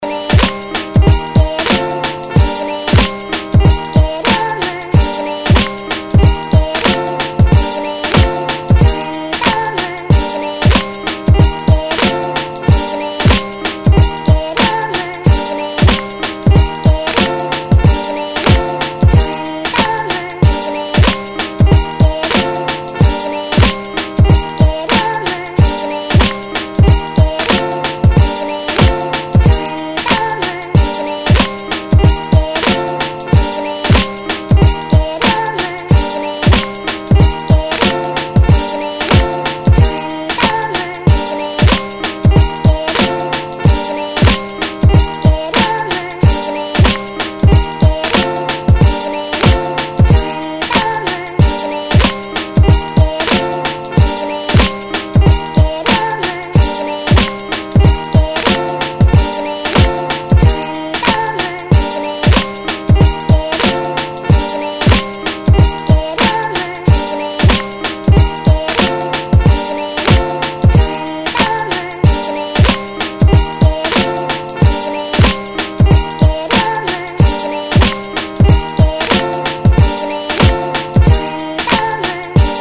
beaty